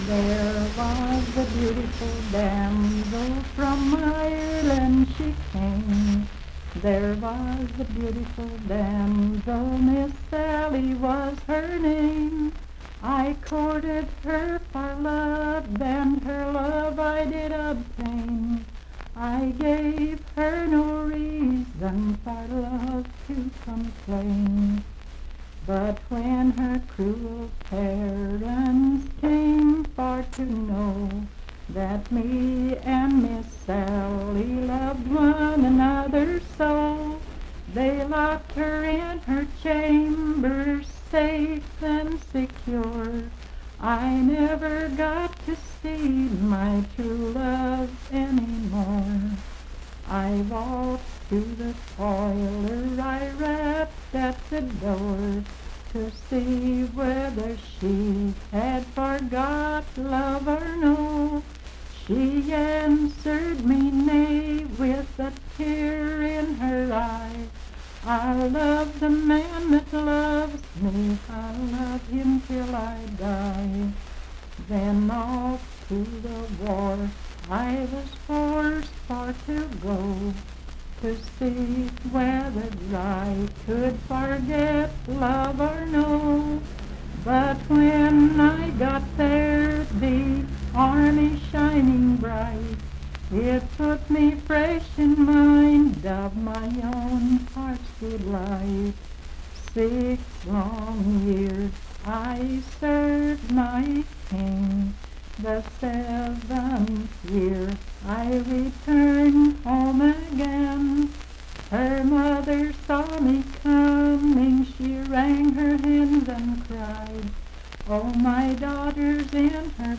Unaccompanied vocal music
in Laurel Dale, W.V..
Voice (sung)